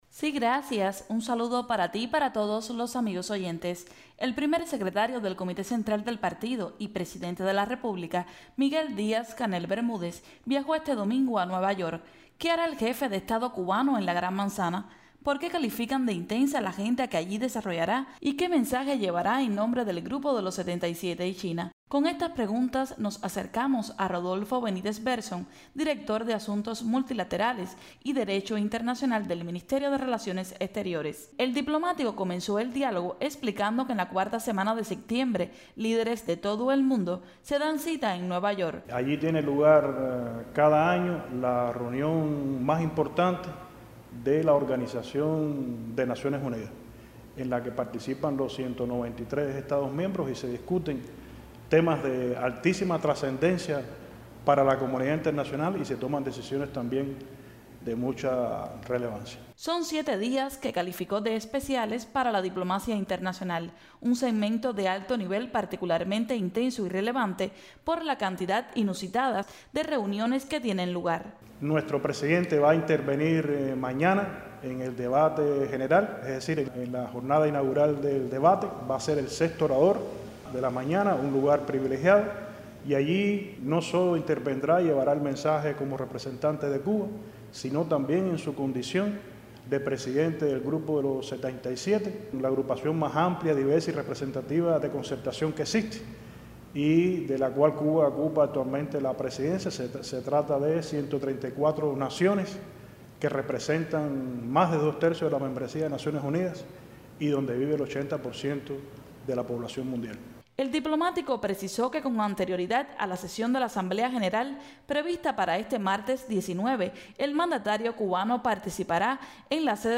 Para conocer un poco más en detalles sobre lo que acontecerá en estas jornadas el equipo de la Presidencia de la República entrevistó a Rodolfo Benítez Verson, director general de Asuntos Multilaterales y Derecho Internacional del Ministerio de Relaciones Exteriores de Cuba.